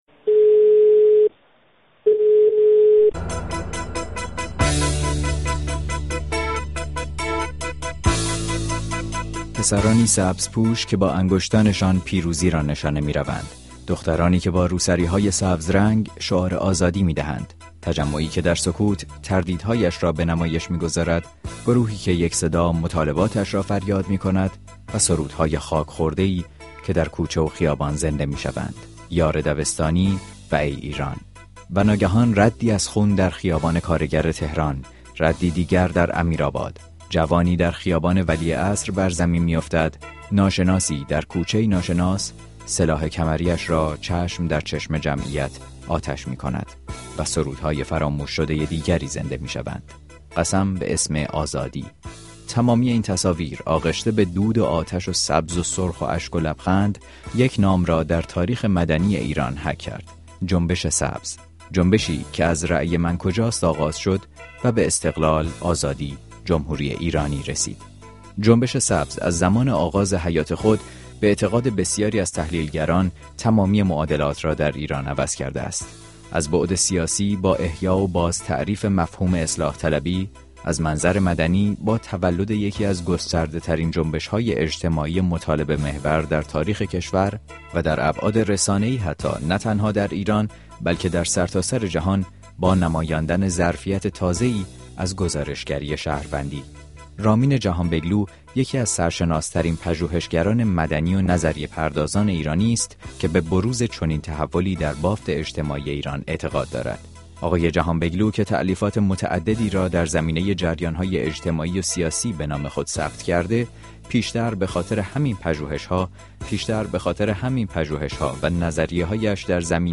با رامین جهانبگلو، پژوهشگر و نظریه‌پرداز